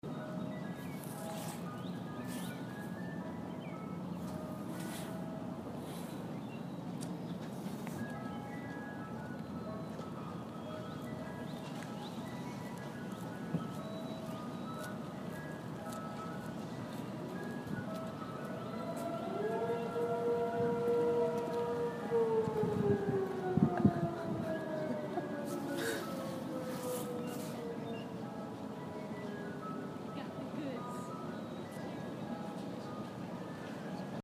Outside of the Joseph G Shapiro Family Hall, there was an ice cream truck on one of the side streets.
Ice cream truck song interrupted by loud sirens.
Pretty eerie.
Ice-Cream-And-Sirens.mp3